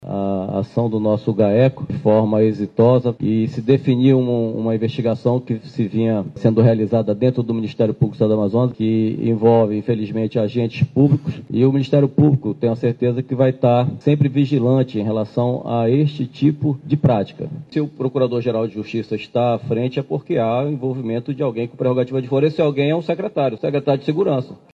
O procurador de Justiça do Ministério Público do Estado do Amazonas (MPAM), Alberto Rodrigues do Nascimento Júnior, confirmou a informação durante coletiva de imprensa, na sede do MP, localizado na Avenida Coronel Teixeira, Zona Oeste de Manaus.